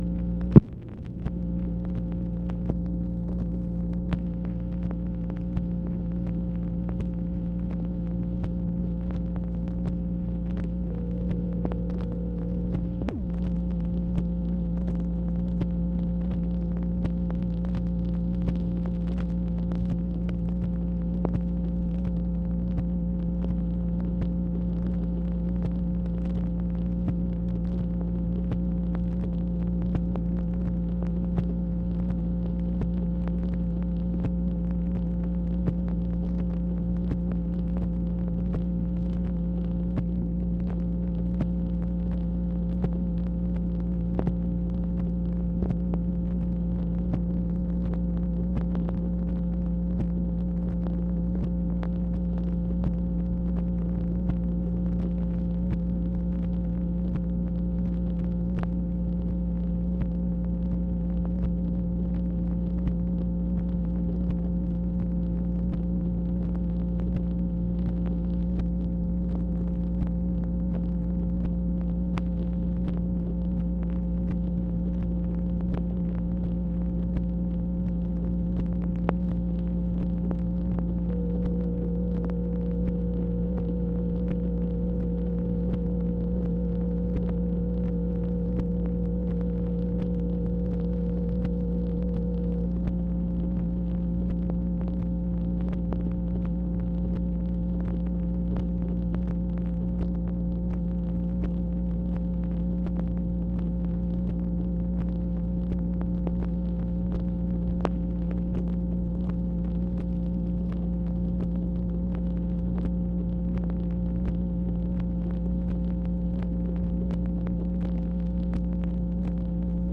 MACHINE NOISE, August 18, 1966
Secret White House Tapes | Lyndon B. Johnson Presidency